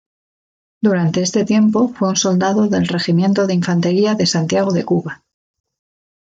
Pronounced as (IPA) /solˈdado/